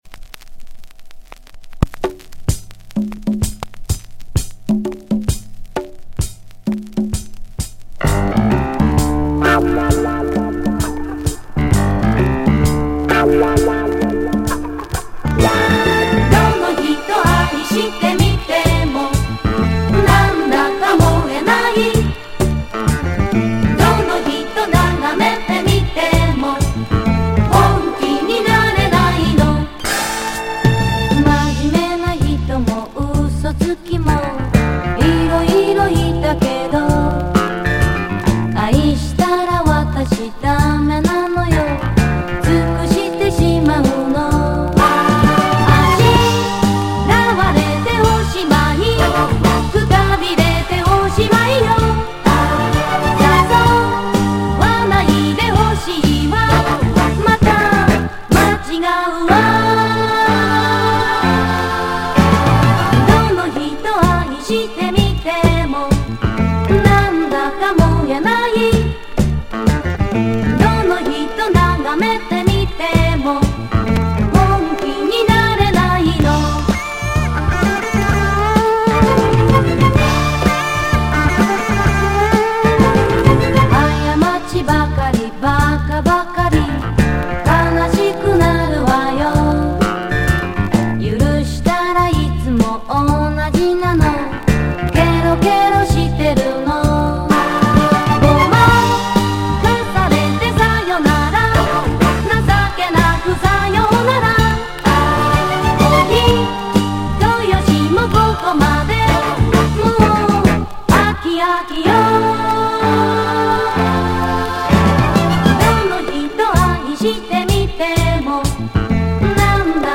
Запись была сделана с винила.